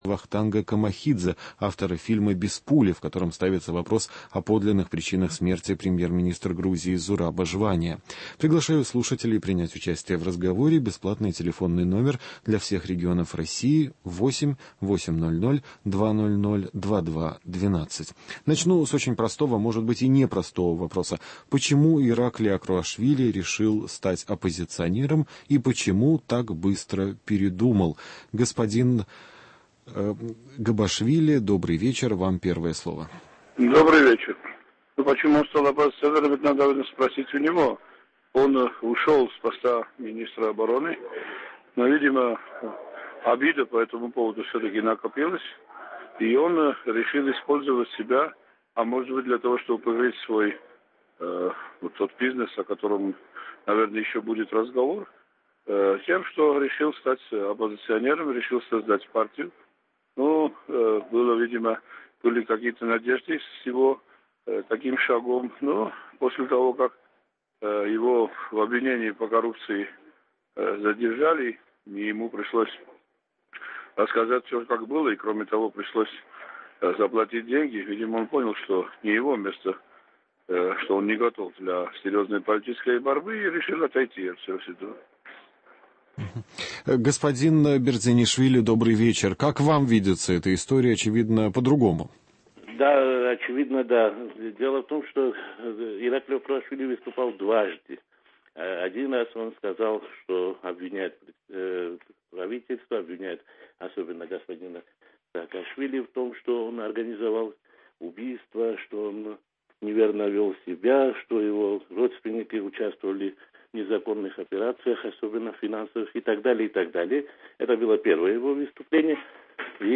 Беседа с экспертами о последних событиях в Грузии, обзор американских еженедельников и российских Интернет-изданий, рубрики «Вопрос недели», «Курьез недели» и «Человек недели».